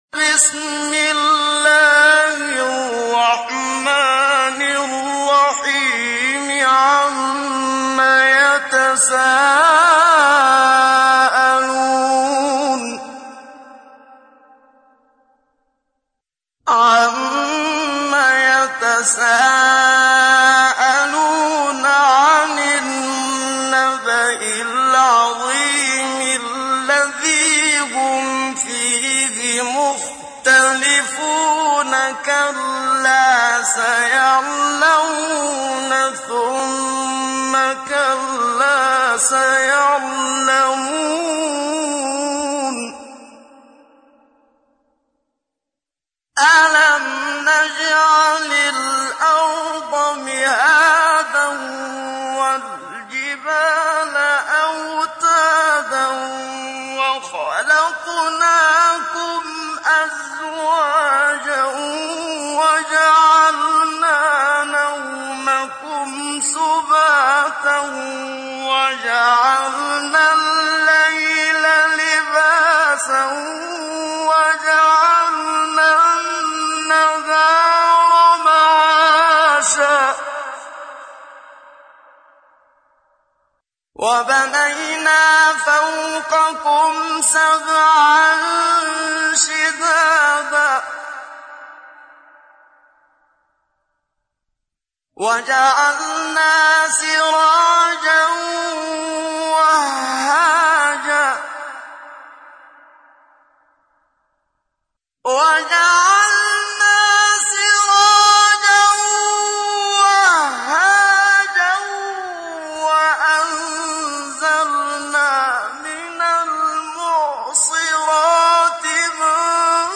تحميل : 78. سورة النبأ / القارئ محمد صديق المنشاوي / القرآن الكريم / موقع يا حسين